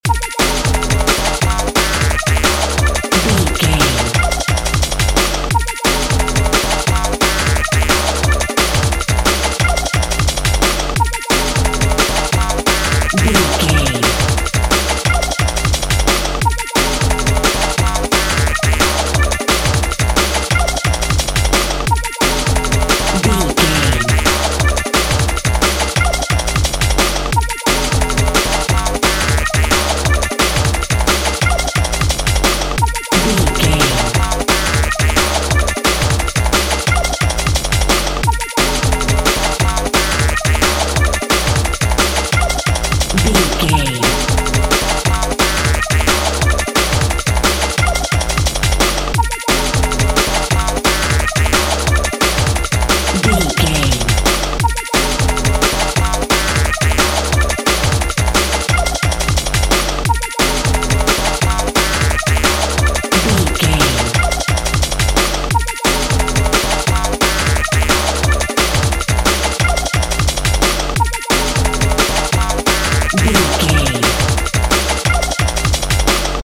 Ionian/Major
B♭
Fast
futuristic
hypnotic
industrial
frantic
uplifting
drum machine
synthesiser
break beat
sub bass
synth lead
synth bass